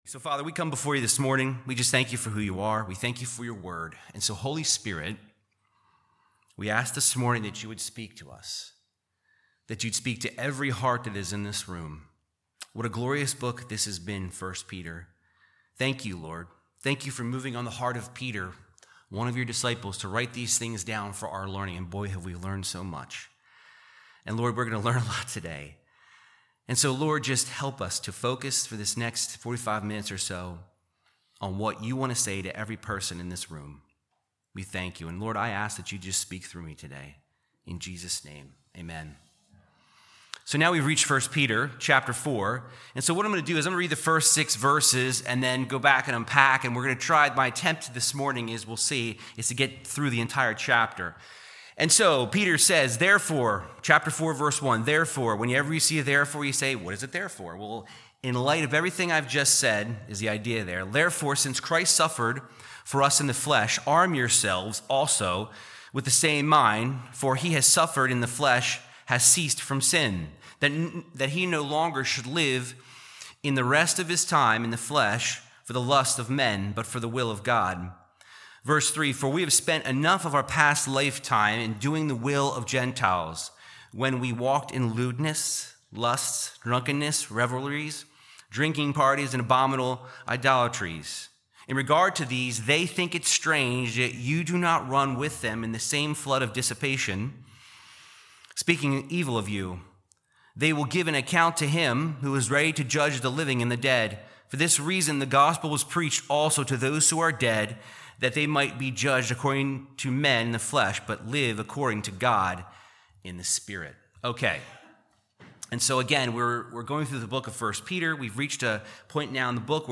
Verse by verse Bible teaching from 1 Peter 4